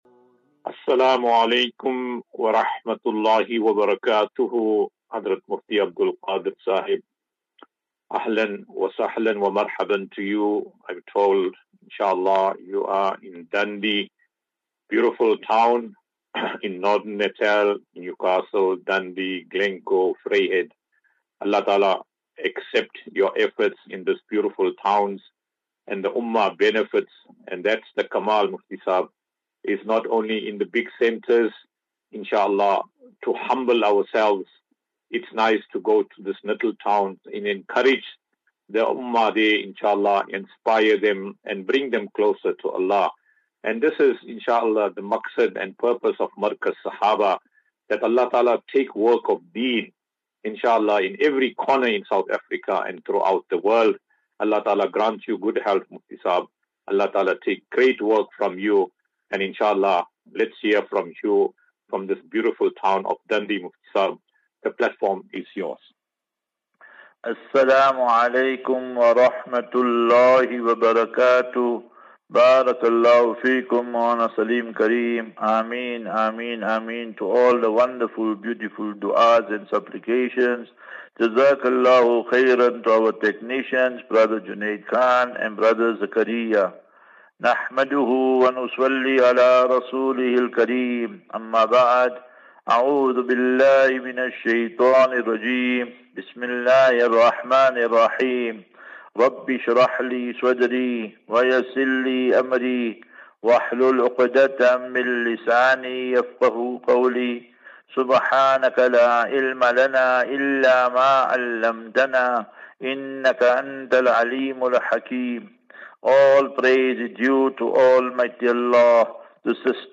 As Safinatu Ilal Jannah Naseeha and Q and A 9 Aug 09 August 2024.